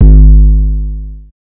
SouthSide Kick Edited (5).wav